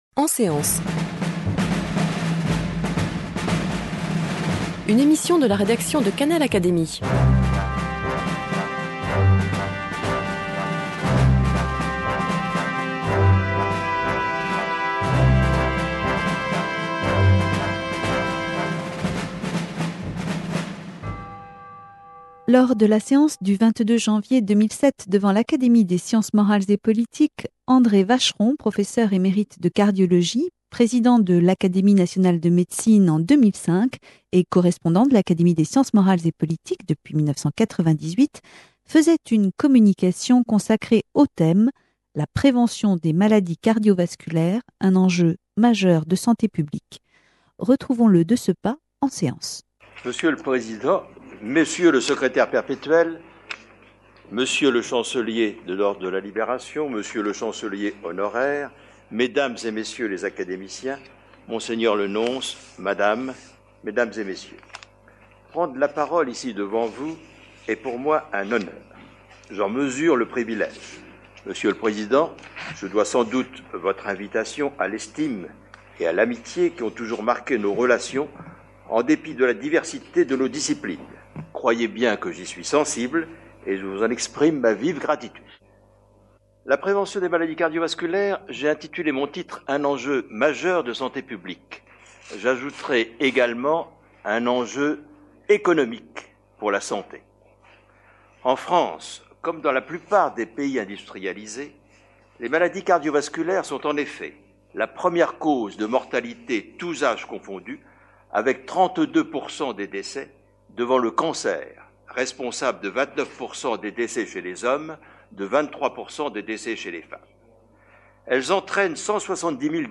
devant l’Académie des Sciences morales et politiques